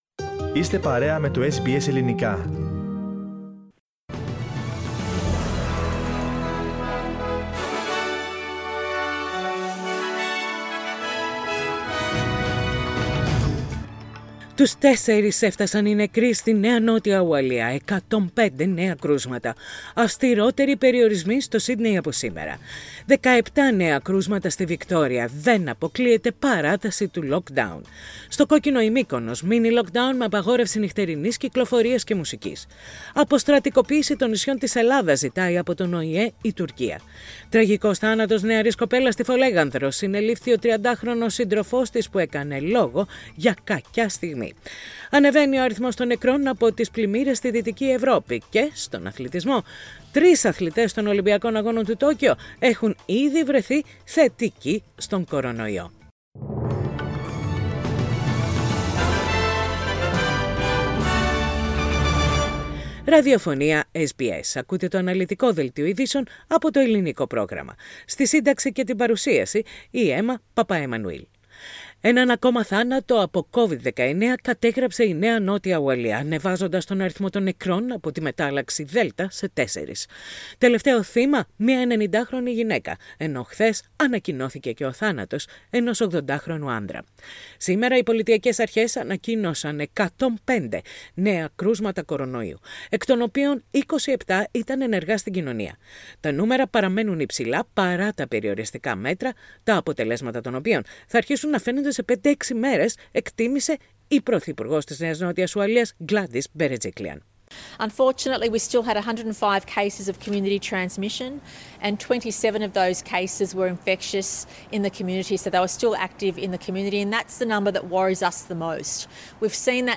News in Greek - Sunday 18.7.21
Main news of the day from SBS radio Greek.